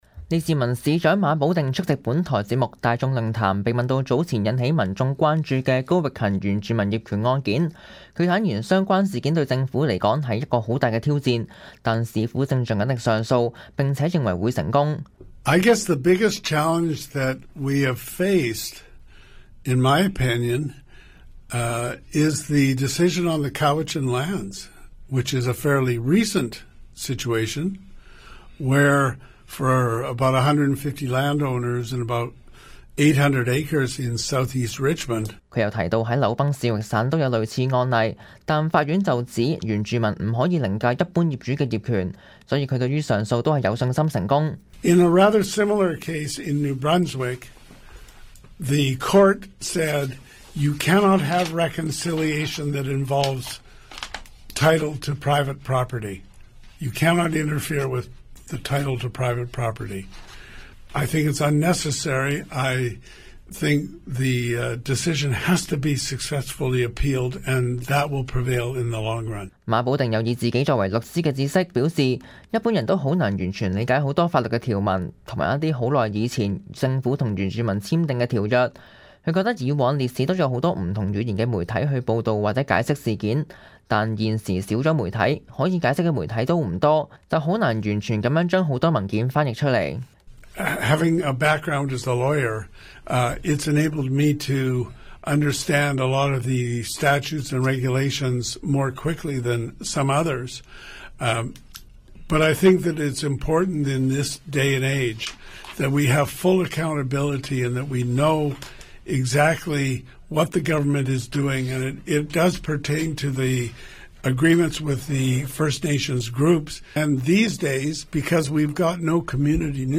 列治文市長馬保定Malcom Brodie出席本台節目《大眾論壇》，被問及早前引起民眾關注的高域勤原住民業權案件，他坦言相關事件對政府而言是一個很大的挑戰，但市府正盡力上訴，並且認為會成功。